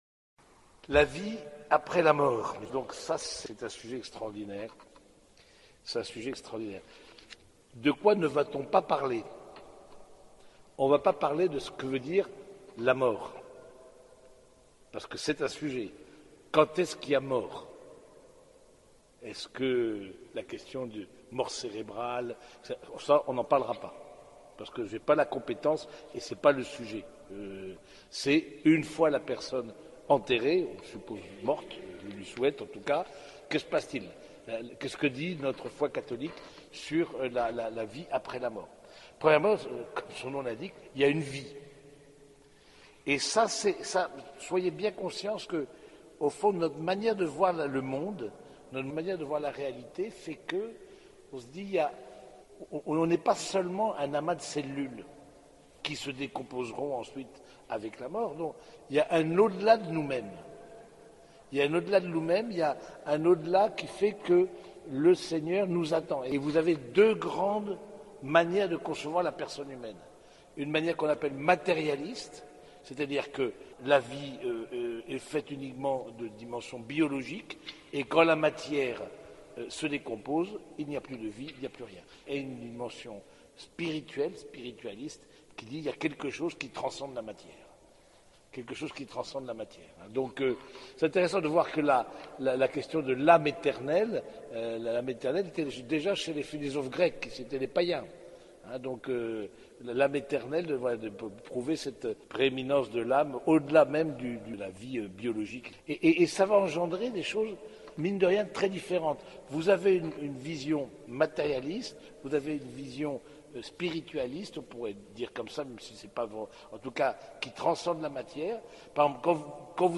Conférence Spi&Spi de mars 2025